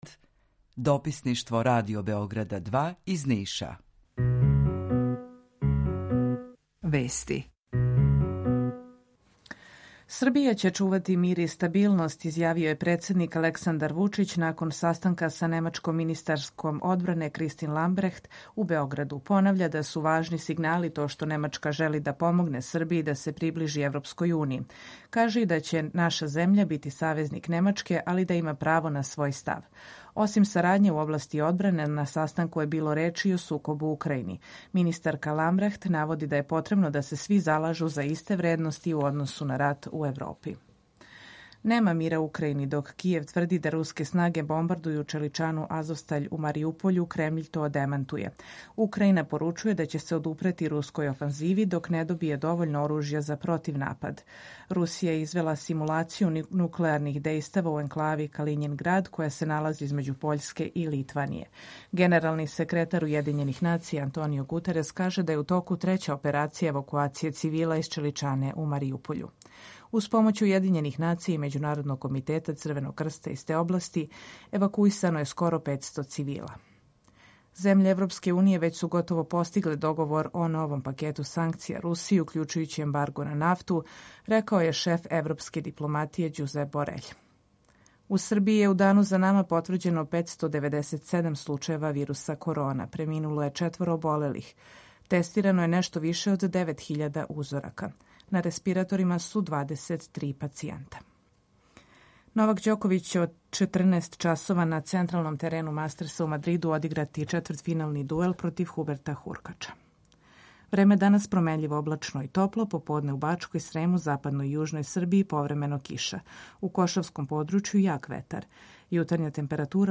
Емисију реализујемо заједно са студиом Радија Републике Српске у Бањалуци и са Радио Новим Садом.
Јутарњи програм из три студија
У два сата, ту је и добра музика, другачија у односу на остале радио-станице.